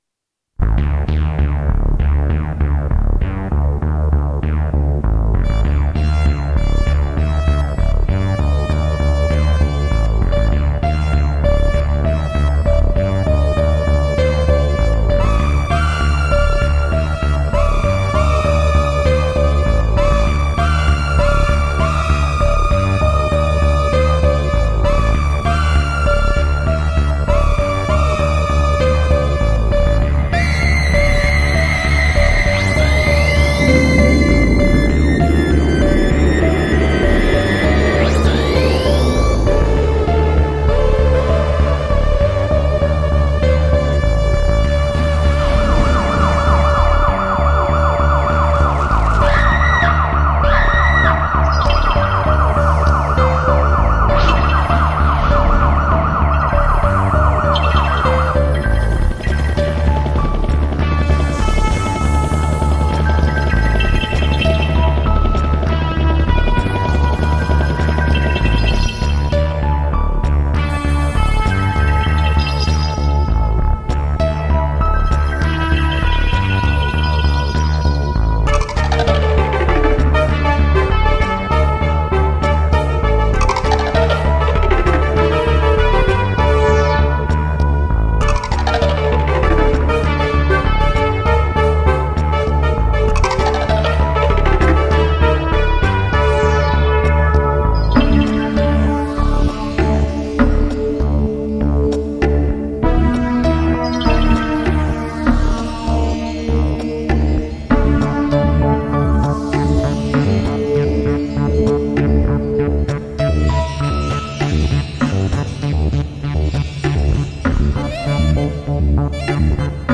Work in electronic music experimentations
Most of my music was produced in an old Atari machine
with a midi clavier and sound software of the 90ies,